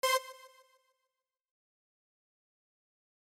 Start by adding a classic delay with about 50% feedback and 30% mix (how loud the delay is).
Here is a short example so you can hear the delay I am using…